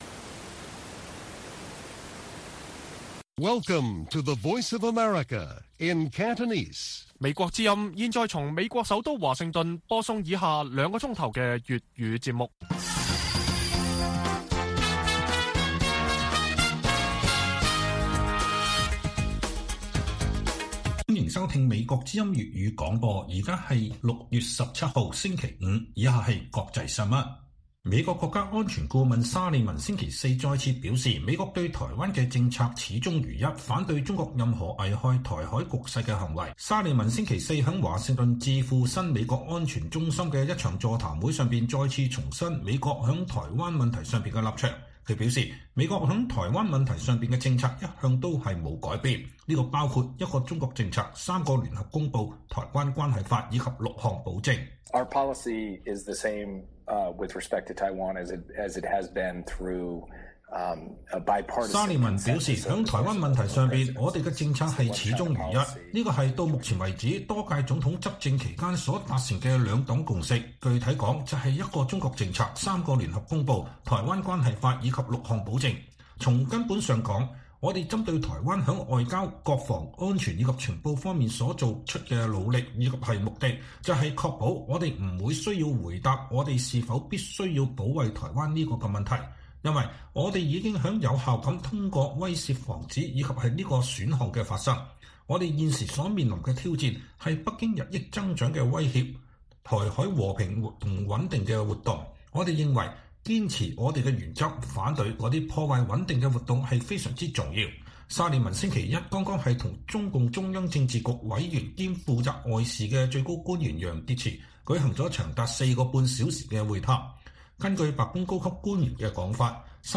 粵語新聞 晚上9-10點：美國國家安全顧問沙利文：美國的對台政策始終如一